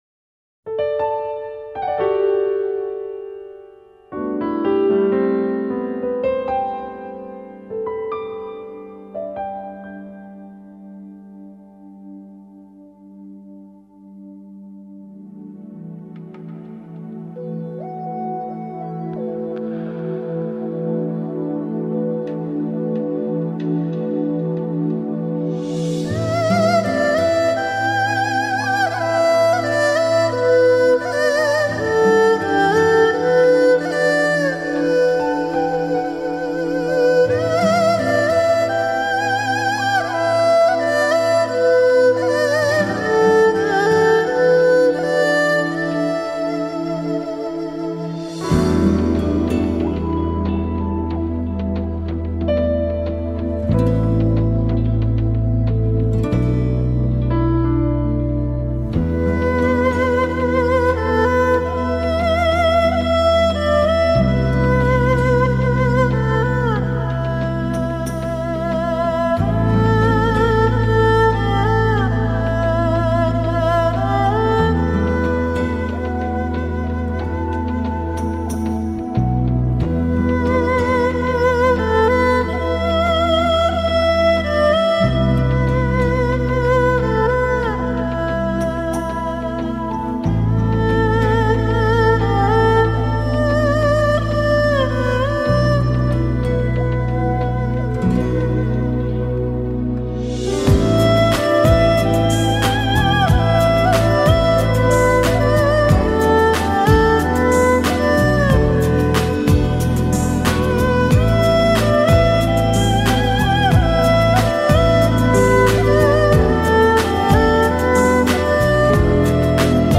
二胡有约二千年的历史。相传二胡起源于中国北方，在两根钢制的弦中间，通过用马尾毛做的琴弓的摩擦发声。